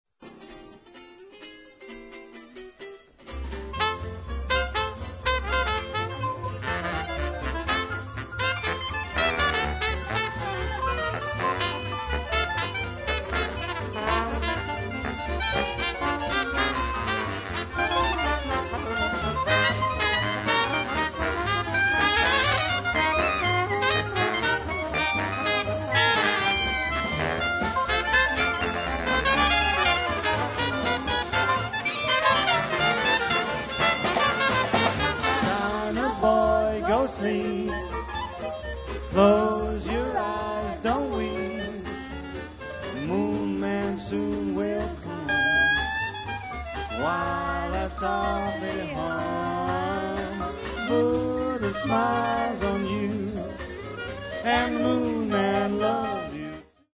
cornetta
trombone
clarinetto
pianoforte
contrabbasso
batteria